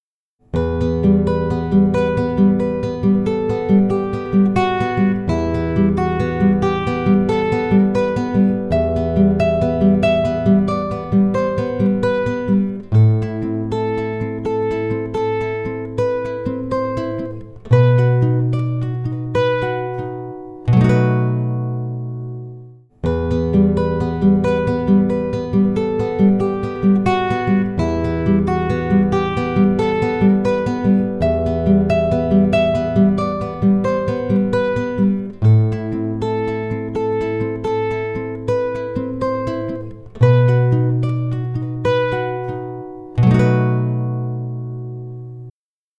In this case two tracks are added to the multi-track (one for condenser microphone output and one for guitar DI output). In advance, the first track is panned 30% left and the other is panned 30% right to have that thick sound.
Basically the guitarist would like to sit in classical guitar position.
There are only two effects used, Reverb and EQ.
These are the reverb setting: Focusrite Reverb plug-in: Using medium jam room presets
classicalguitarmp3demo.mp3